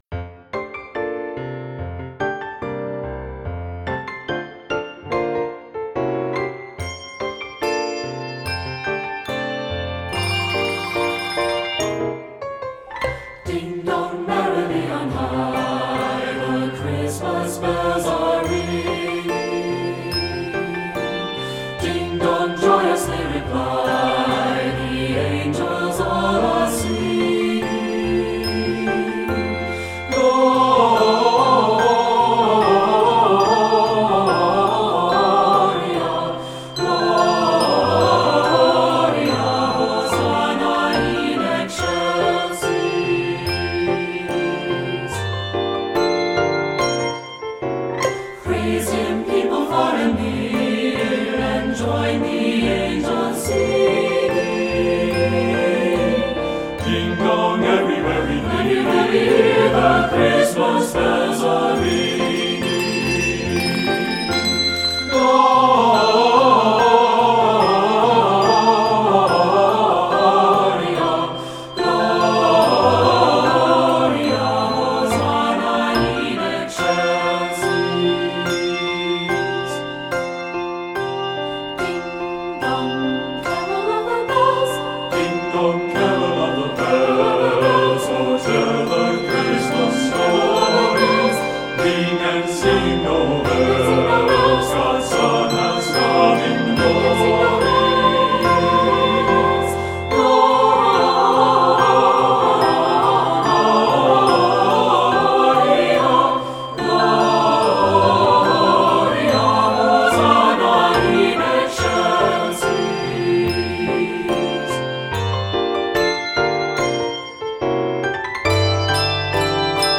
For SATB choir with an optional part for children’s choir